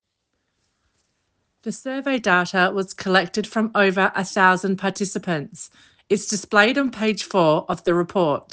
3. Australian accent: Data